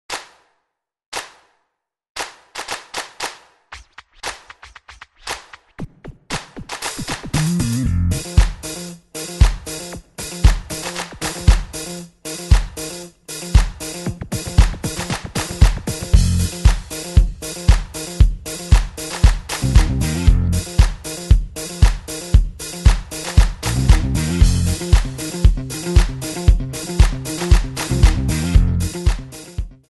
Fm
MPEG 1 Layer 3 (Stereo)
Backing track Karaoke
Pop, Disco, Musical/Film/TV, Duets, 2000s